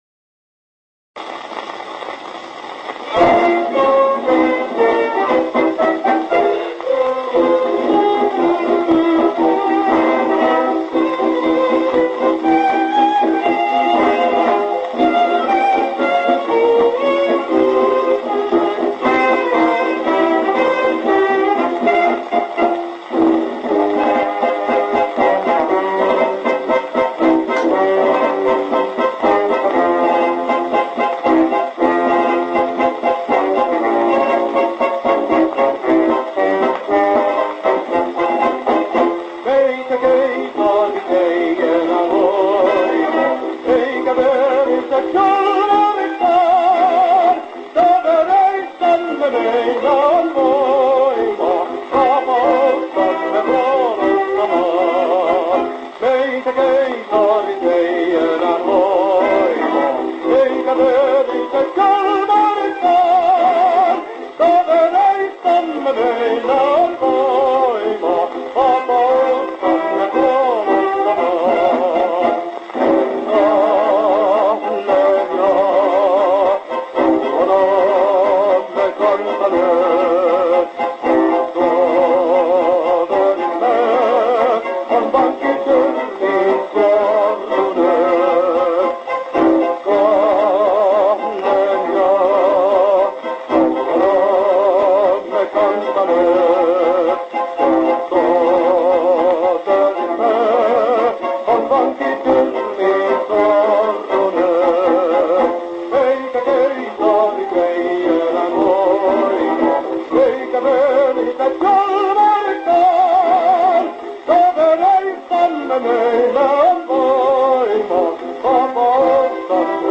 tenor, with orchestra